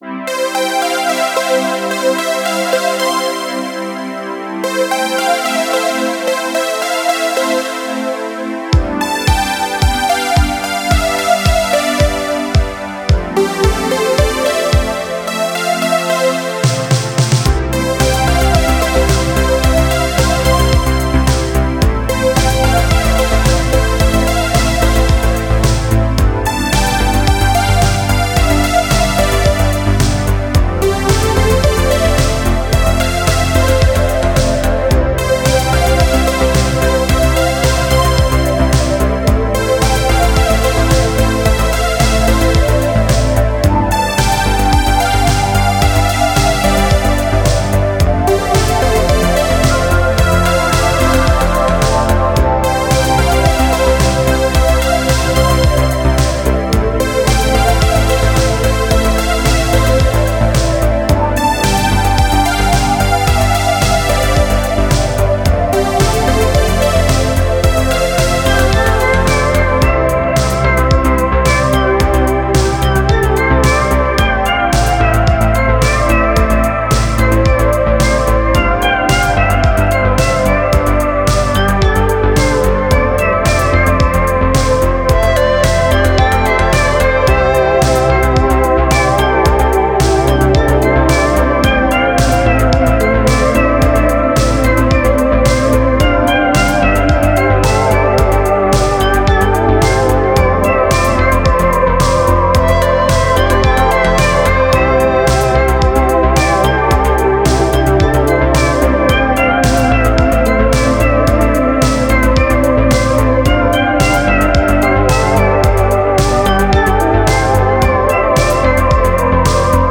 Жанр: Synthwave